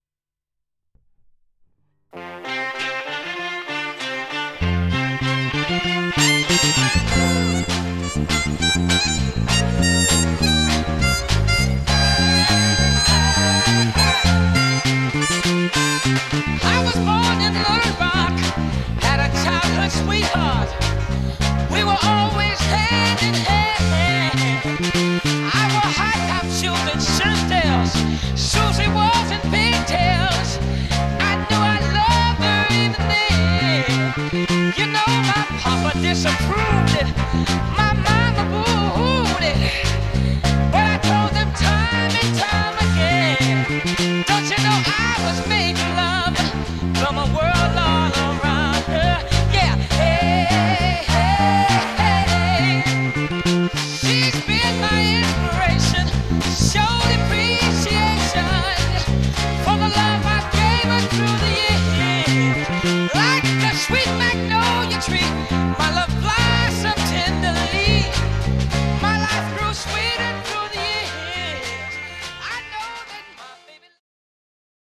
Jedná se Precision z dílny custom shopu v úpravě heavy relic.
Je víc konkrétní, má trochu více výšek, ale zároveň má pevný základ palisandru a jeho kulatost.
Zvukově naprosté dělo, ačkoliv díky hlazenkám není 100% univerzální, jak uslyšíte z nahrávek.